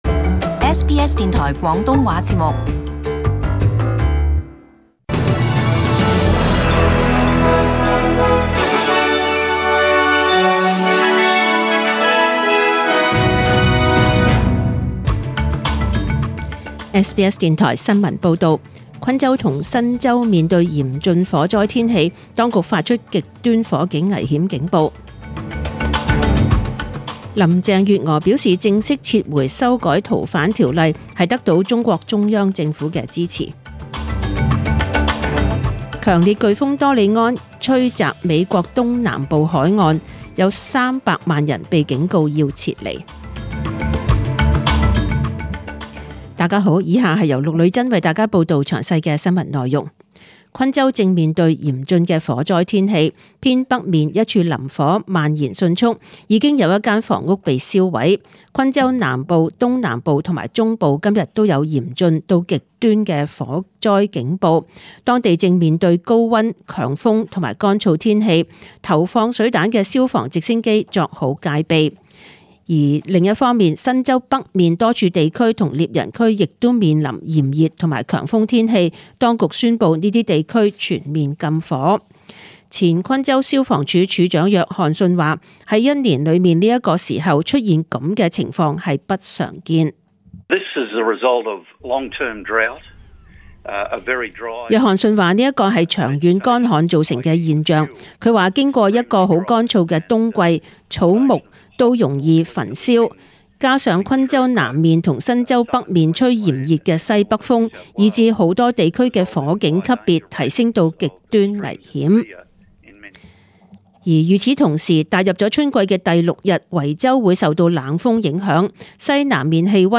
Detailed morning news bulletin